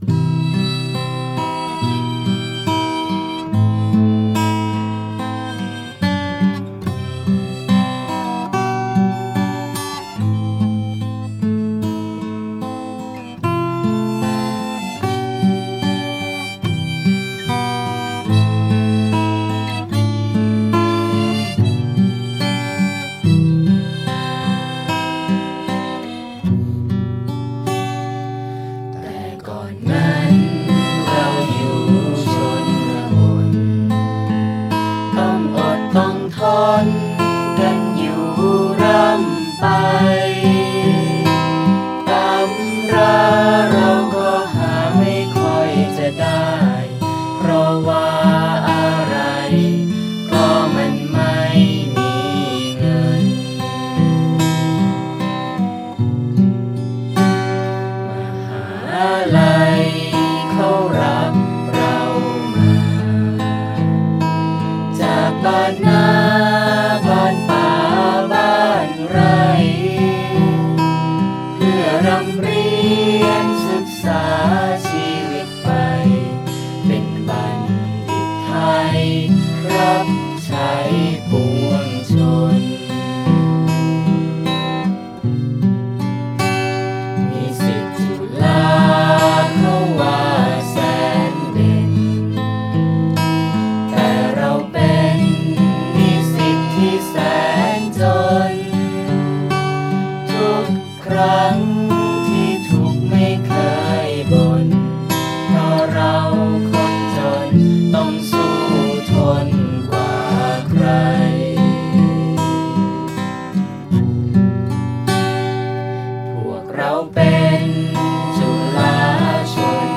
ขับร้อง//ดนตรี โดย.....นิสิตปัจจุบันโครงการจุฬาฯ-ชนบท เนื่องในงานจุฬาฯ วิชาการ ปี 2548